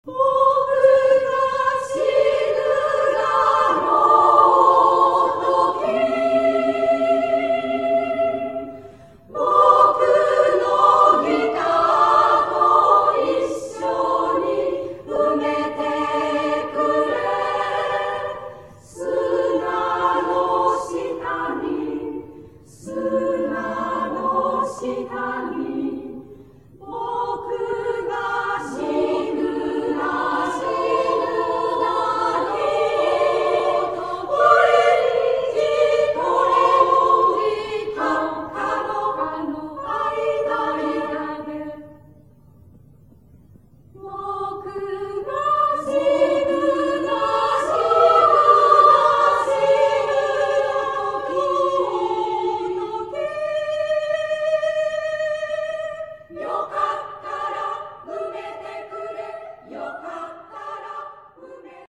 女声合唱団「道」 はじめてのコンサート
女声合唱団「道」のはじめてのコンサートを平成６年４月２４日（日）に開きました。